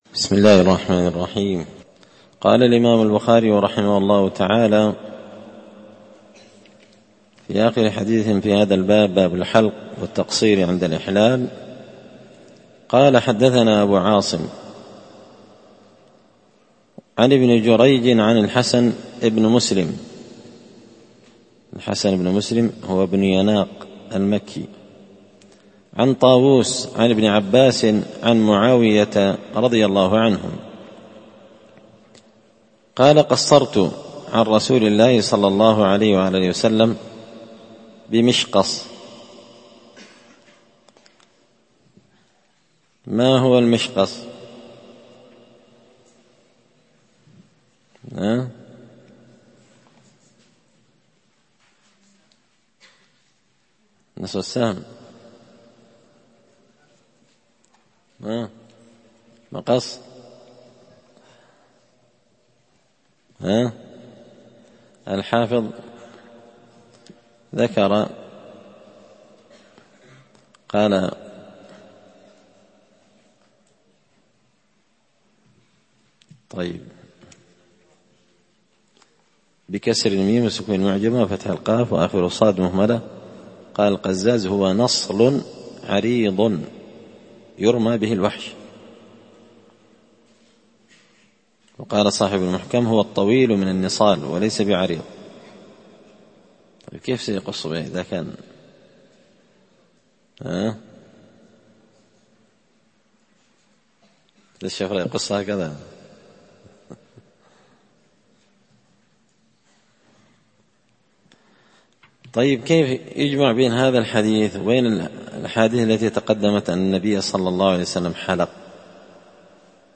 كتاب الحج من شرح صحيح البخاري – الدرس 112